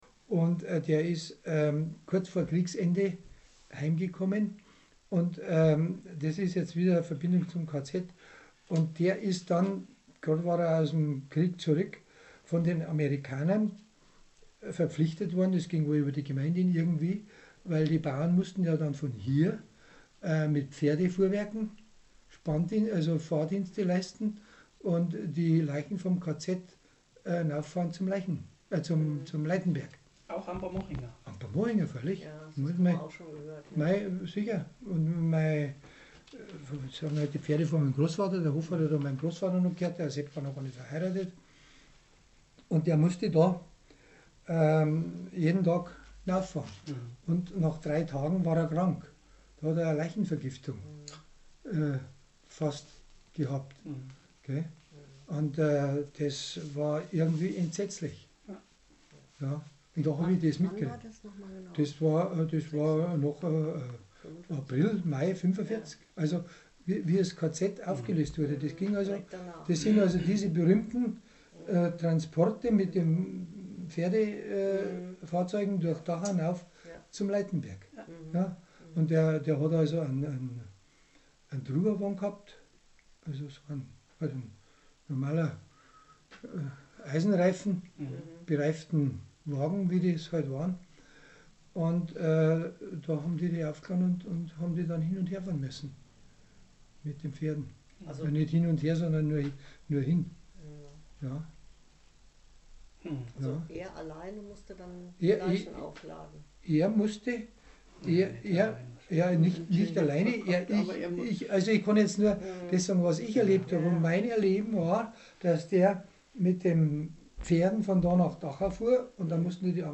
Zeitzeugeninterviews Autor